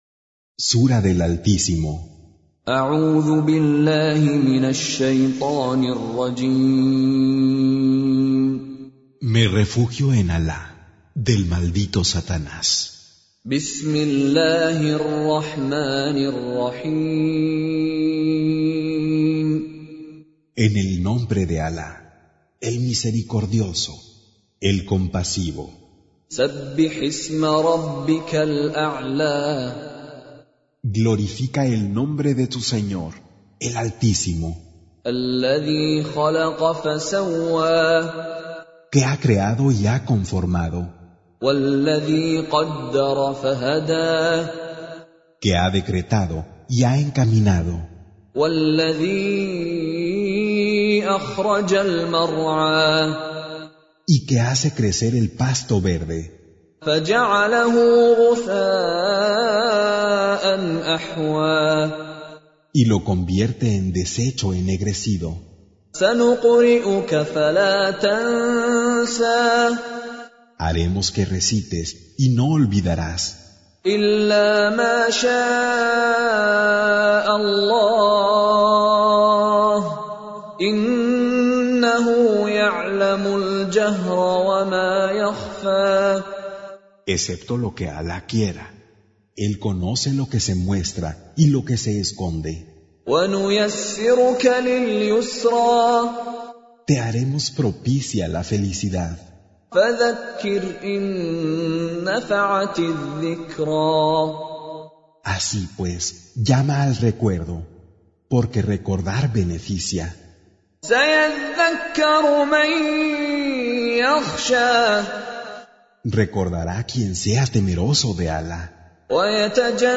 87. Surah Al-A'l� سورة الأعلى Audio Quran Tarjuman Translation Recitation Tarjumah Transliteration Home Of Spanish Translation With Mishary Alafasi :: Traducción al español del Sagrado Corán - Con Reciter Mishary Alafasi - Audio Quran Listing Reciters, Qur'an Audio, Quran
Surah Repeating تكرار السورة Download Surah حمّل السورة Reciting Mutarjamah Translation Audio for 87.